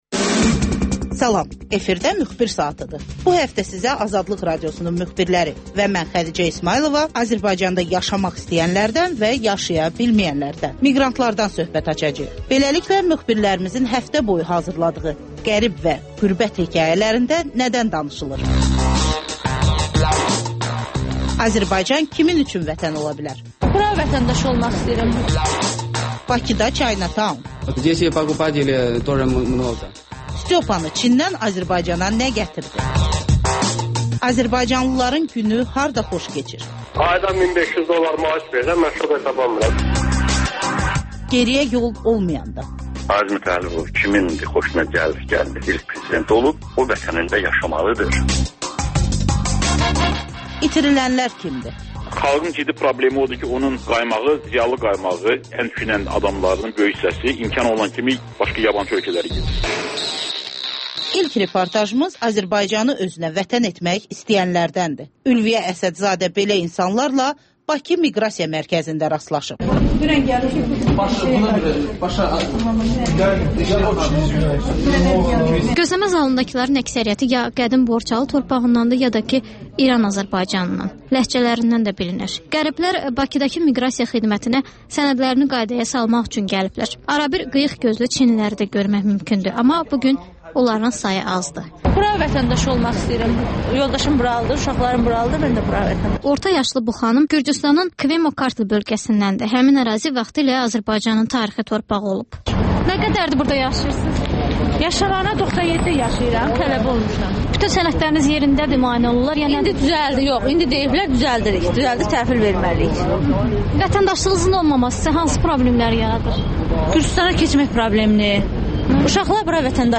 Müxbirlərimizin həftə ərzində hazırladıqları ən yaxşı reportajlardan ibarət paket